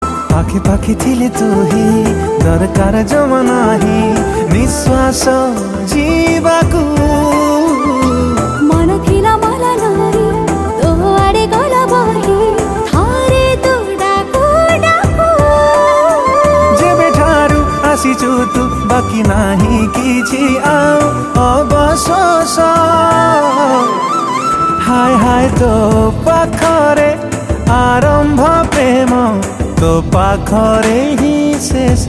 Odia Ringtones
love song